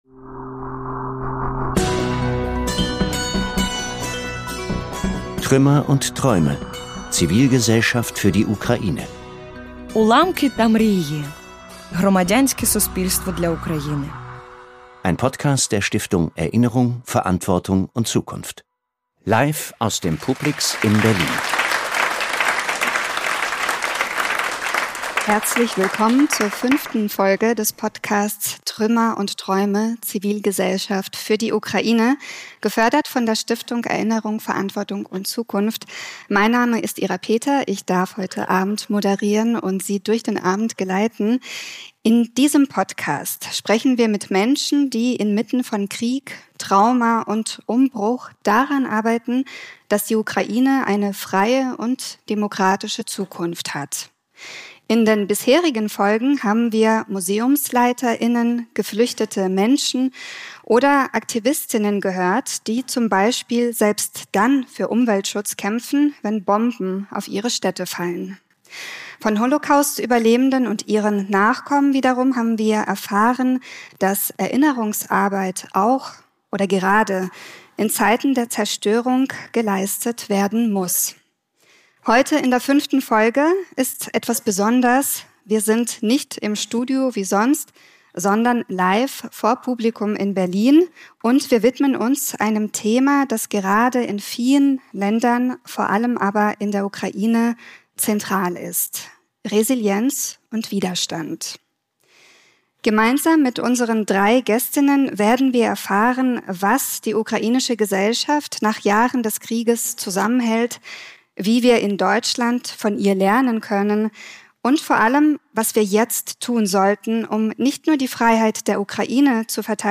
Resilienz und Widerstand – Was wir von der ukrainischen Zivilgesellschaft lernen können (live) ~ Trümmer & Träume. Zivilgesellschaft für die Ukraine Podcast